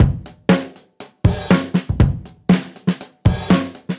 Index of /musicradar/dusty-funk-samples/Beats/120bpm
DF_BeatC_120-02.wav